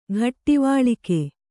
♪ ghattivāḷike